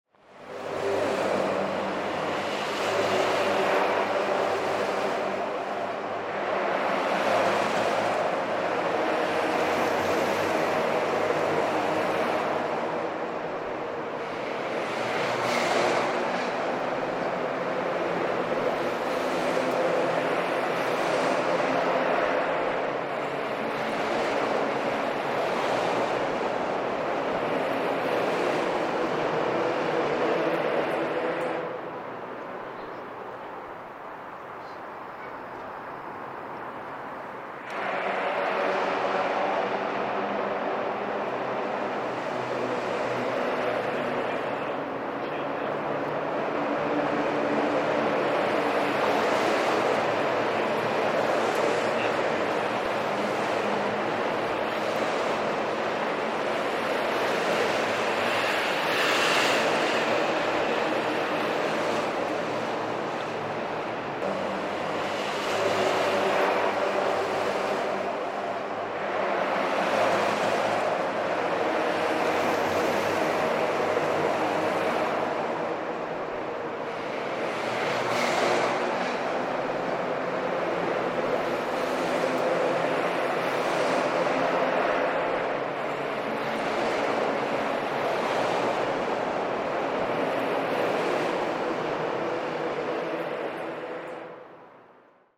Tyres on steel over Hawthorne Bridge
The Hawthorne Bridge, built in 1910 is the oldest vertical lift bridge in the USA and the oldest highway bridge in Portland, Oregon. I stood beneath it one sunny summer day, as the traffic passed over the open steel grills of the carriageway creating the rushing tones in this recording.